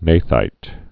(nāthīt, năthīt)